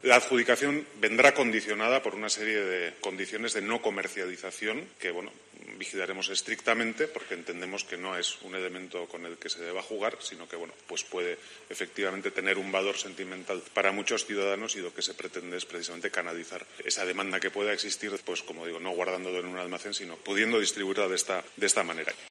Eneko Goia, alcalde de San Sebastian